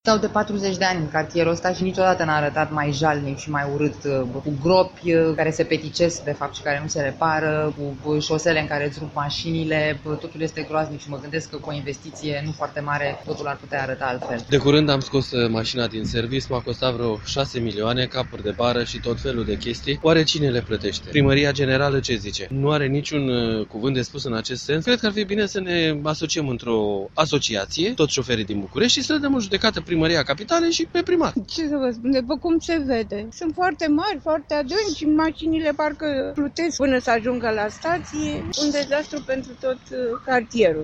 vox-strazi-13-feb.mp3